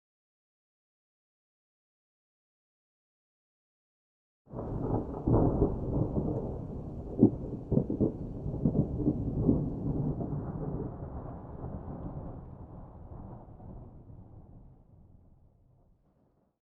pre_storm_3.ogg